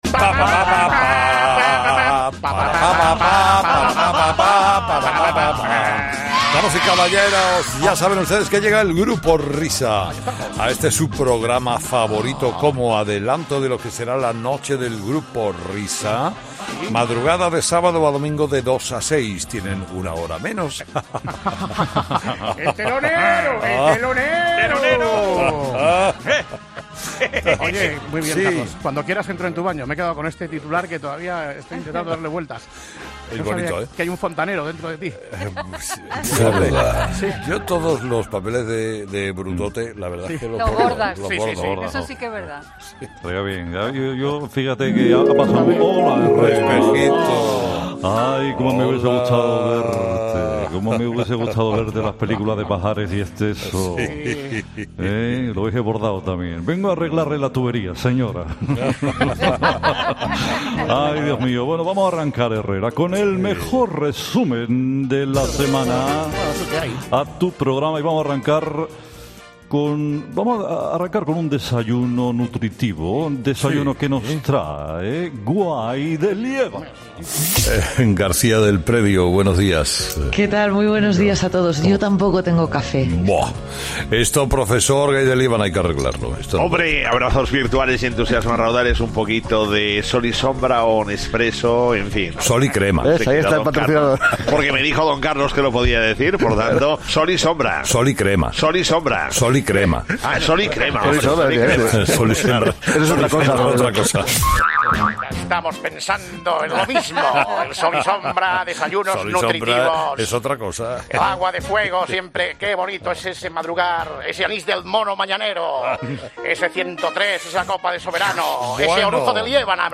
No te pierdas la sesión de control al programa de Herrera que este viernes ha protagonizado el trío de cómicos
Este viernes el Grupo Risa ha vuelto al estudio de Carlos Herrera para analizar los momentos más comprometidos de la semana.